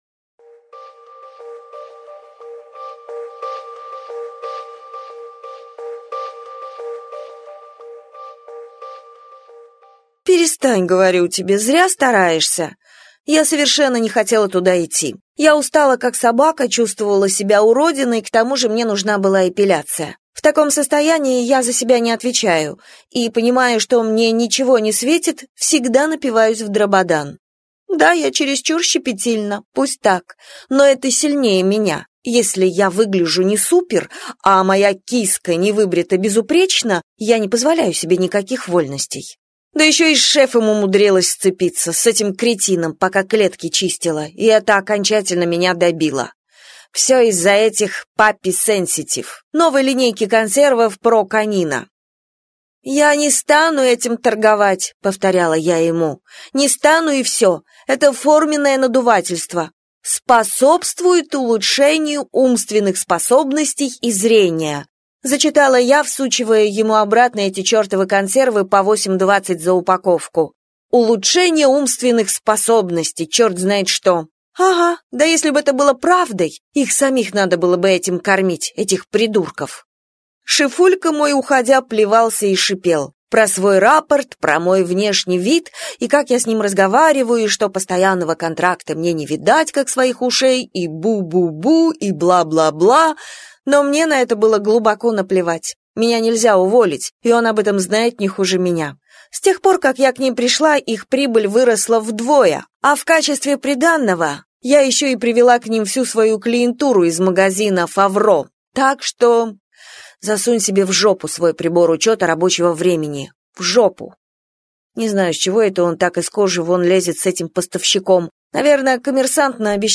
Аудиокнига Я признаюсь - купить, скачать и слушать онлайн | КнигоПоиск